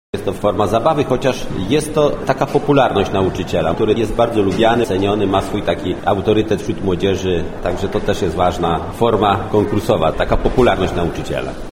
Rozstrzygnięto również organizowany przez Powiat Stalowowolski konkurs na „Belfra Roku”. Mówi starosta stalowowolski Janusz Zarzeczny: